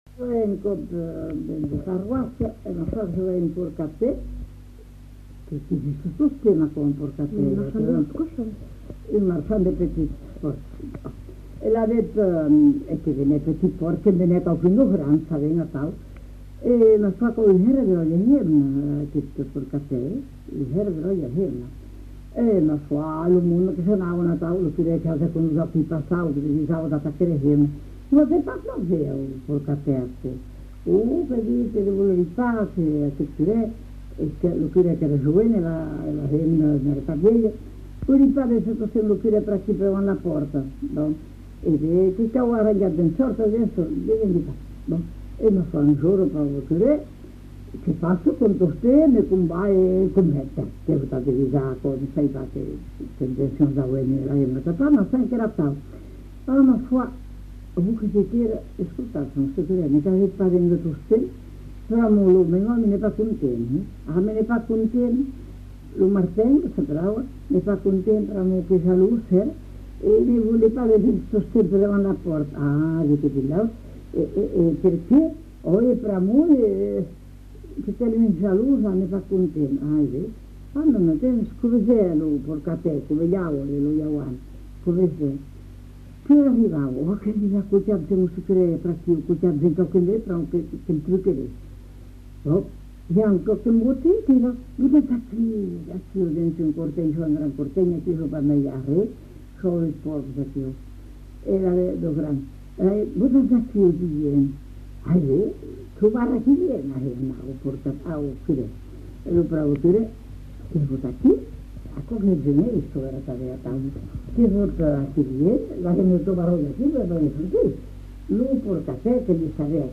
Aire culturelle : Marsan
Genre : conte-légende-récit
Effectif : 1
Type de voix : voix de femme
Production du son : parlé